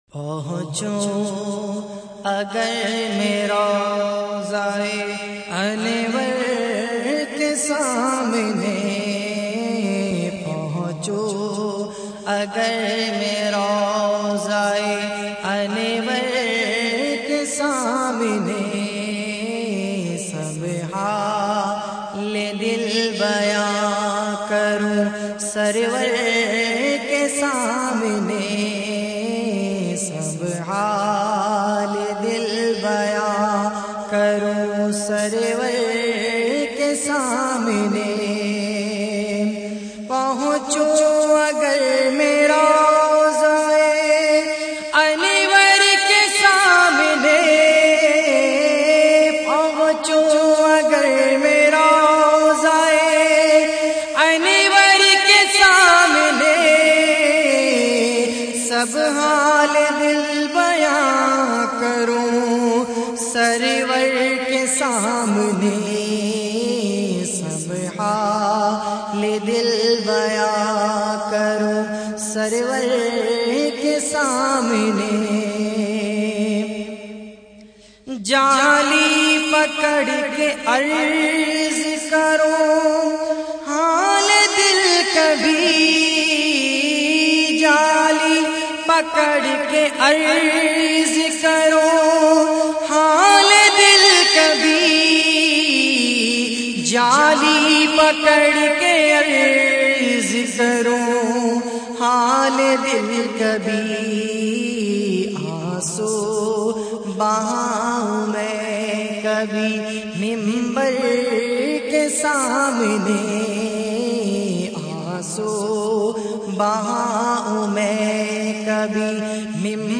urdu naats